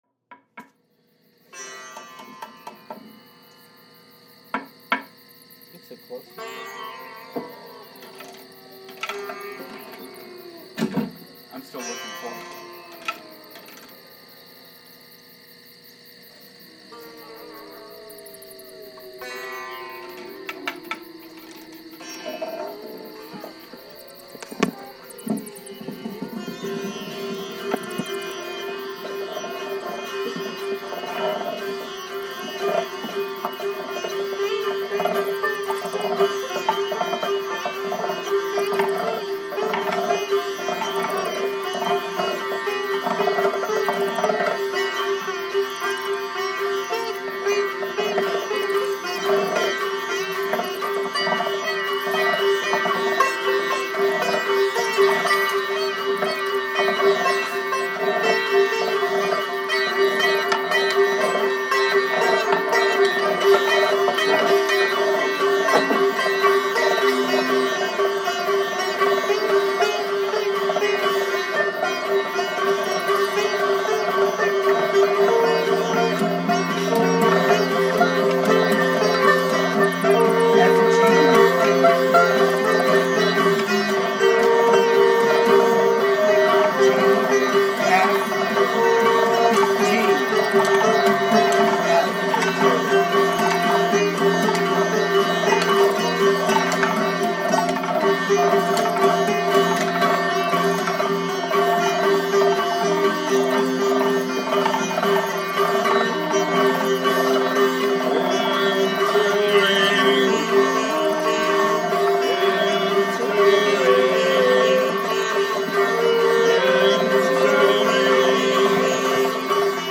ALL MUSIC IS IMPROVISED ON SITE